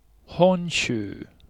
• What: Honshu (本州; ほんしゅう) in Japanese.